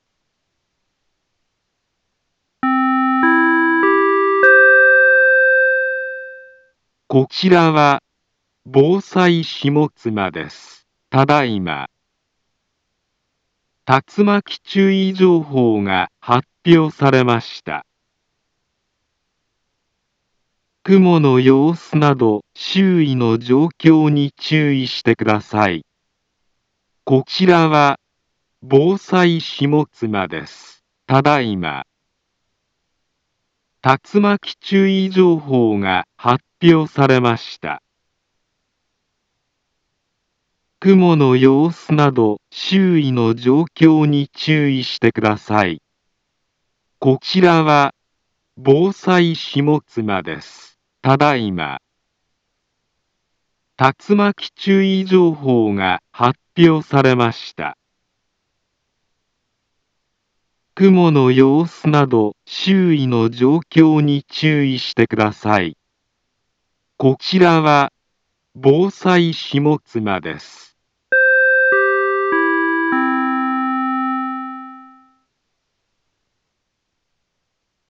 Back Home Ｊアラート情報 音声放送 再生 災害情報 カテゴリ：J-ALERT 登録日時：2025-09-02 22:08:26 インフォメーション：茨城県南部は、竜巻などの激しい突風が発生しやすい気象状況になっています。